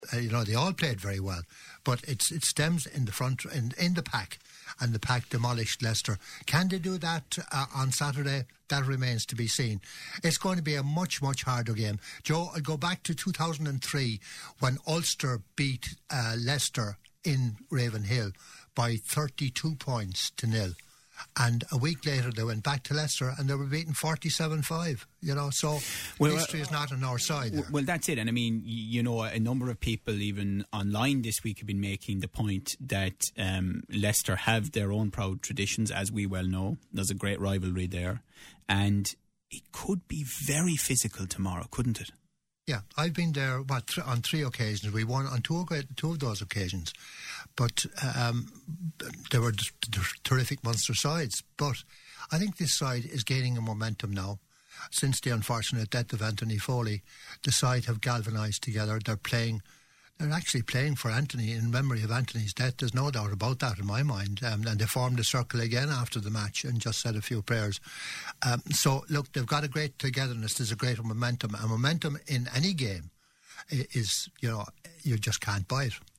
It's a big game for Munster tomorrow as they look to keep their winning run going - you can hear live commentary of their clash with Leciester on Live 95fm from 3.15 tomorrow. Our own sports panel were previewing the game today - take a listen below.